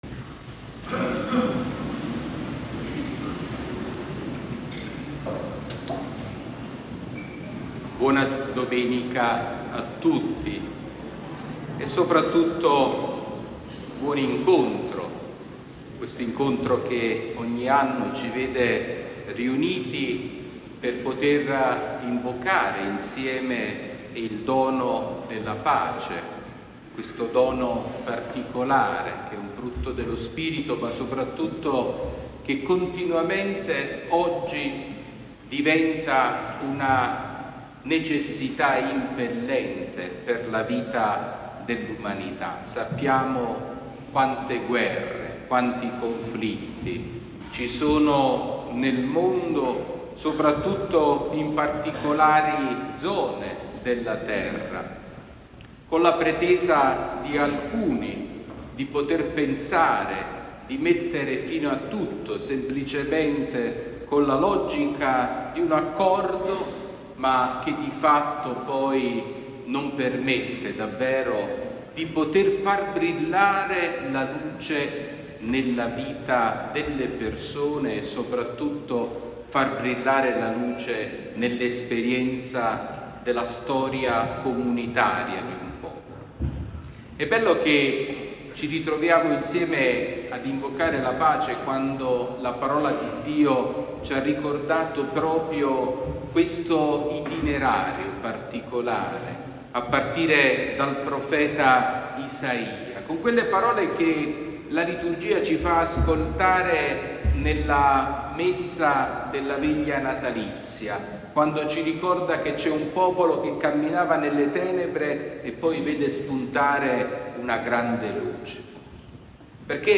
Omelia di Mons. Sabino Iannuzzi durante la Santa Messa per l’appuntamento di Azione Cattolica “Terra in pace”
Omelia-Vescovo-Sabino-marcia-della-Pace-25-1-26.mp3